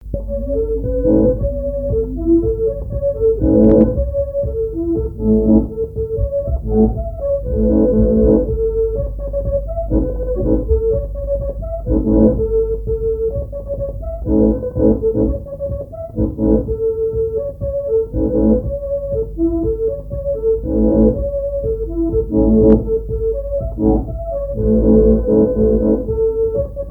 à marcher
Répertoire à l'accordéon diatonique
Pièce musicale inédite